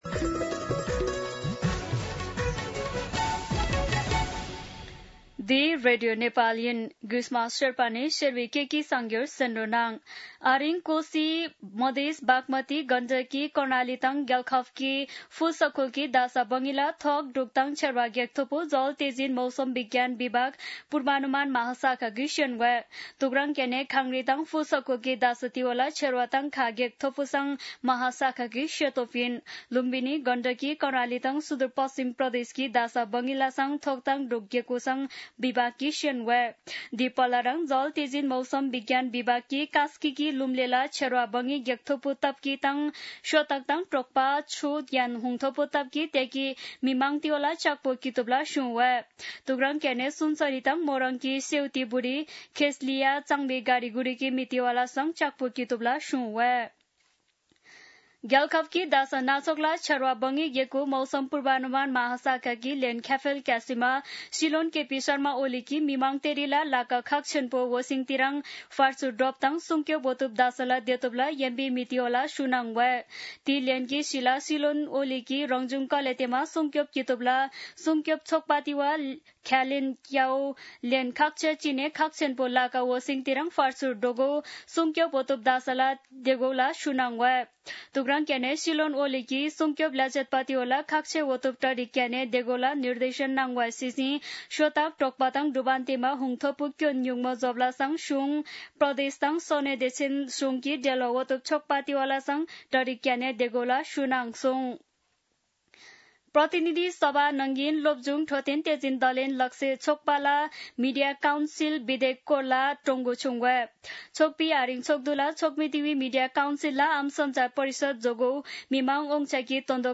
शेर्पा भाषाको समाचार : ४ साउन , २०८२
Sherpa-News-04.mp3